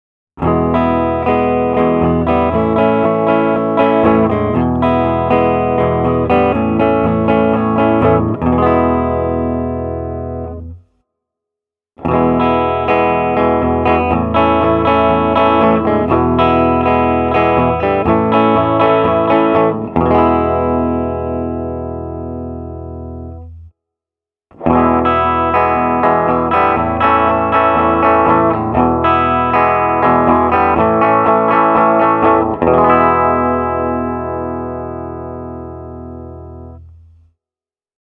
This is what my Vox Escort sounds like:
Vox Escort & Epi Casino – clean
Reverb was added at mixdown.
vox-escort-epi-casino.mp3